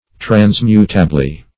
Trans*mut"a*bly, adv.